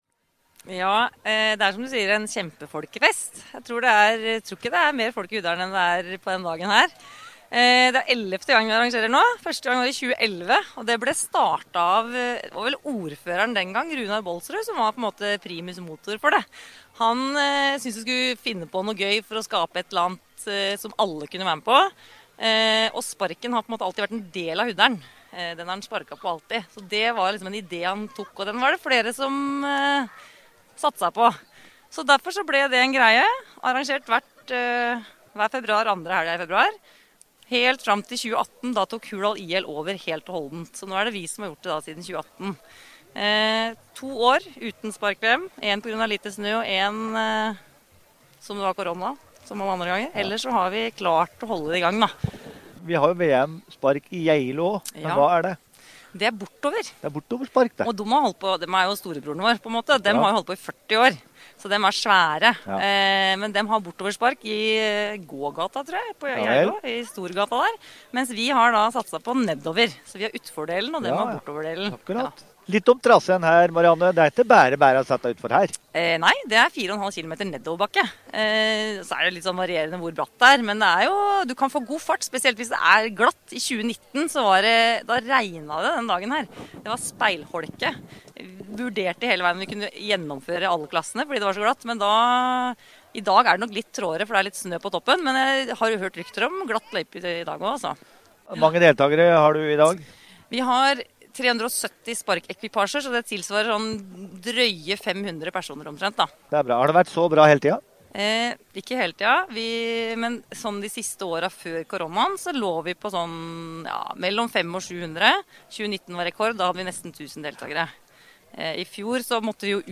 Den 11. utgaven av Spark VM Utfor gikk av stablen 11. februar 2023 i Hurdal.